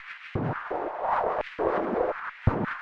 Index of /musicradar/rhythmic-inspiration-samples/85bpm
RI_RhythNoise_85-04.wav